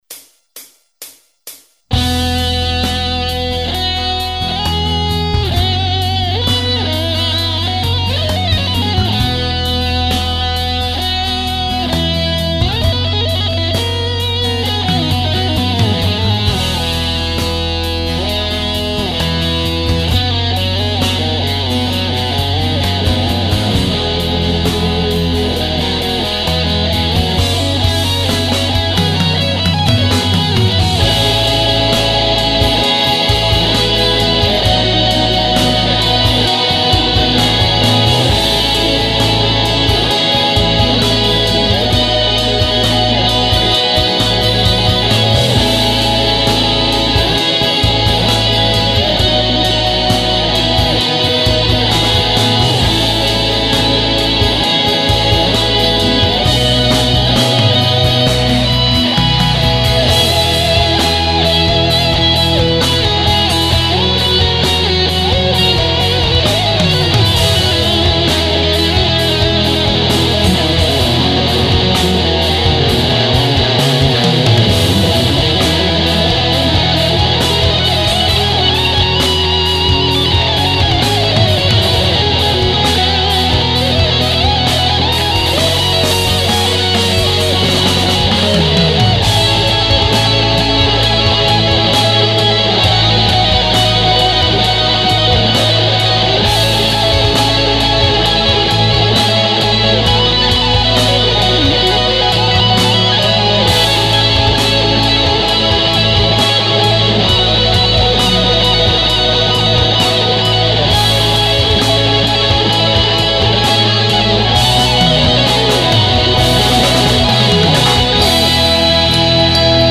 Ukažte co umíte a do připravených podkladů nahrejte vlastní sólo !
Harmonie je jednoduchá, kytara do toho „hraje sama“ :), invenci se meze nekladou, kytara může být jedna nebo mnoho, a samozřejme ani ten podklad není dogma.